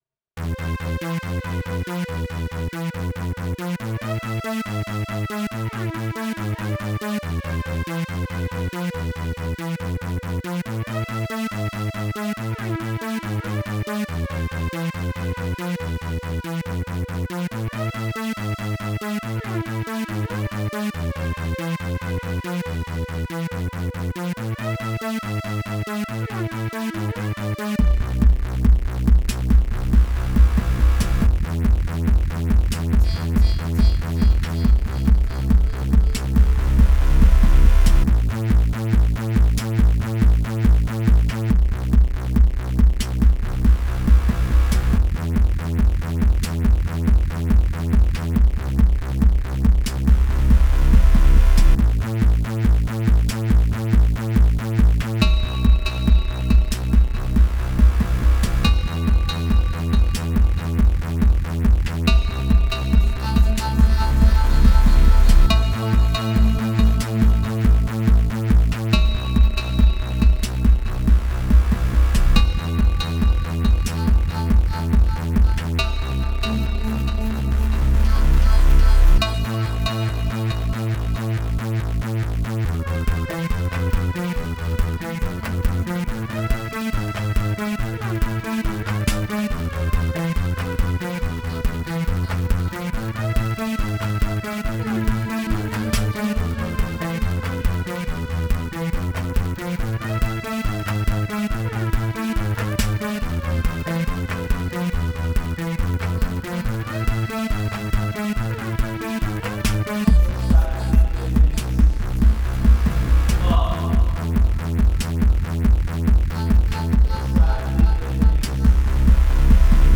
Genre: IDM, Minimal Wave, Synth-pop.